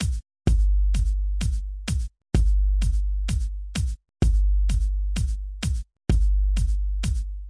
基本4/4节拍120bpm " 节拍120bpm 01
描述：基本4/4击败120bpm
Tag: 回路 节奏 drumloop 120BPM 节拍 量化 有节奏 常规